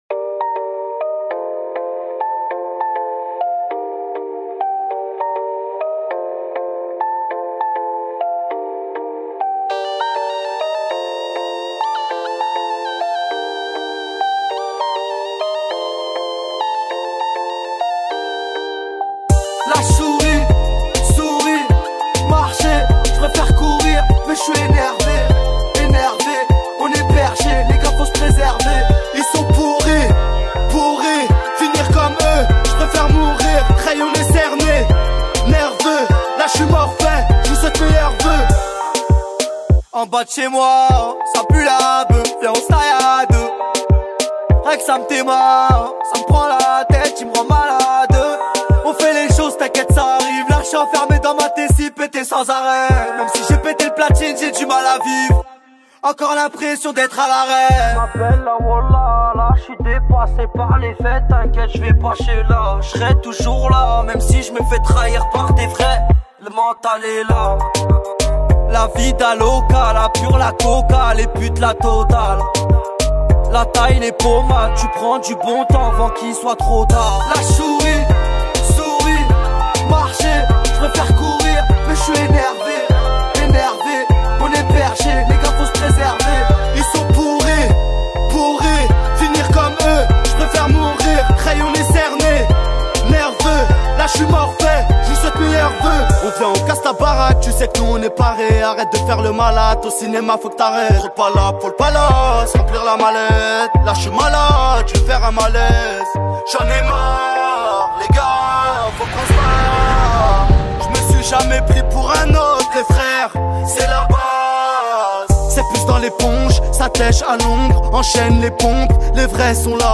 # Rap